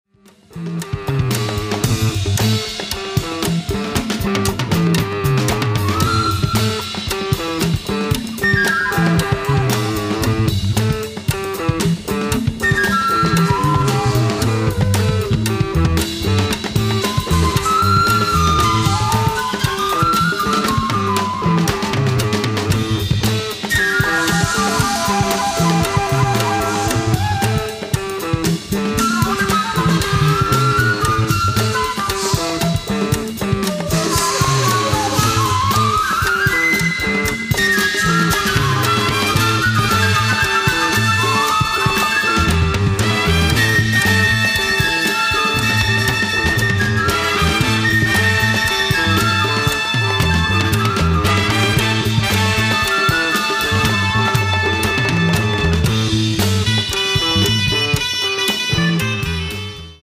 drums
trumpet/flugelhorn
elec.guitar/fork guitar/sitar